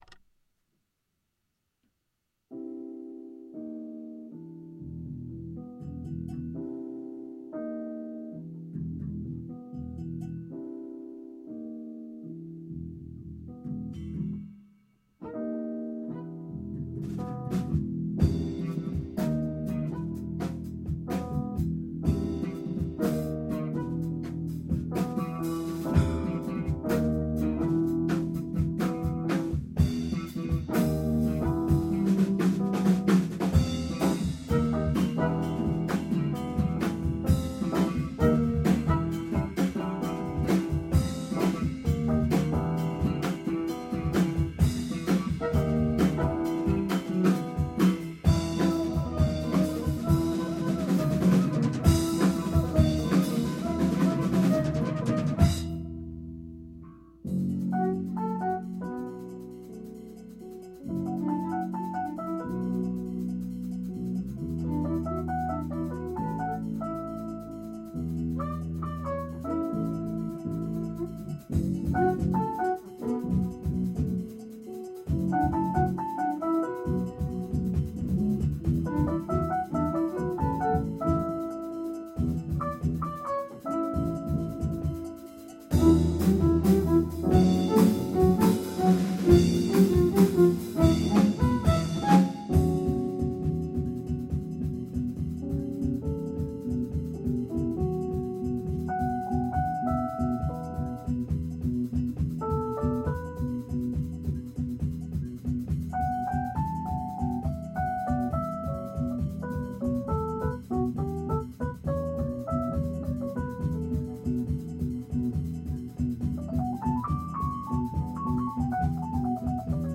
groupe de jazz fusion